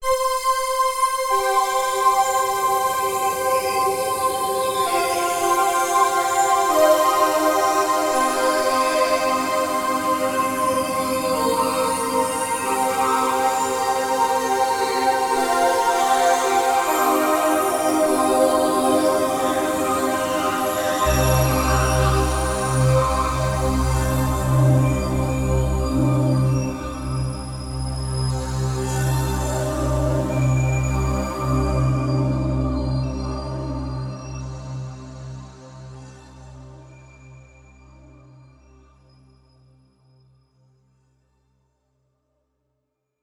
Pad I
waldorf_quantum_test__pad_1.mp3